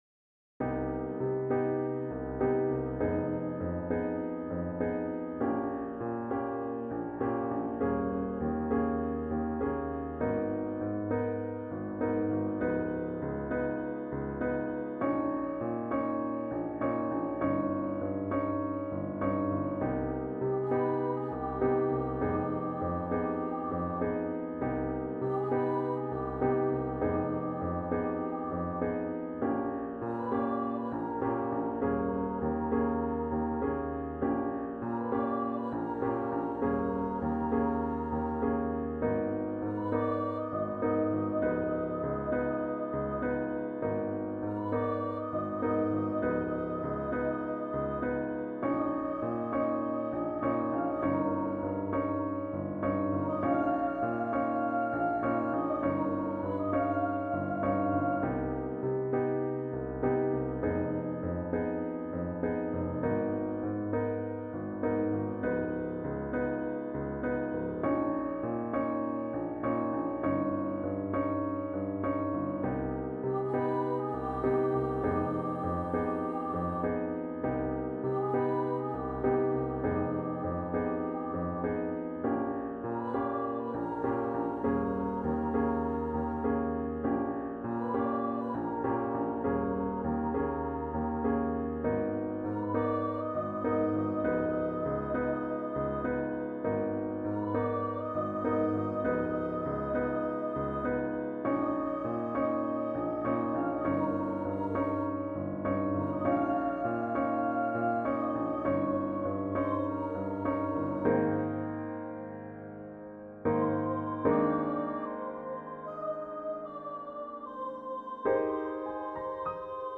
Here is a musical reaction: Cairo Spring , for soprano solo and piano.